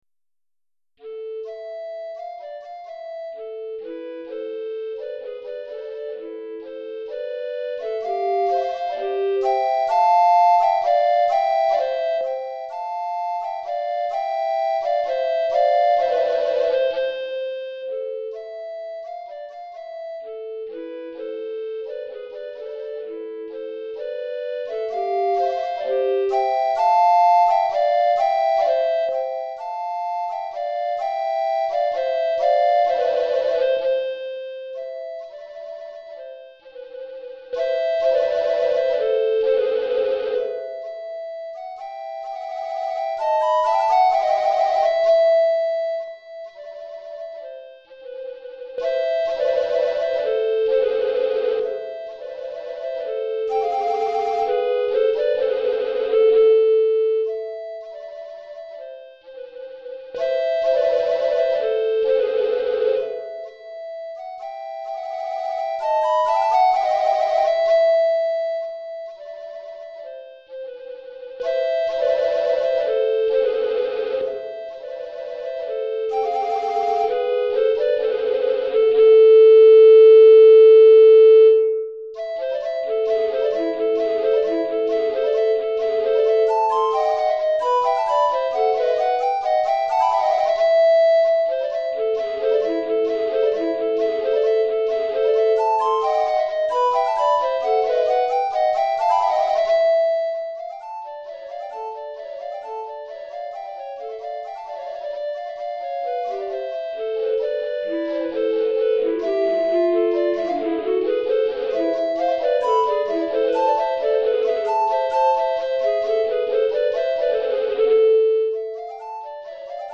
Répertoire pour Flûte à bec - 2 Flûtes à Bec Soprano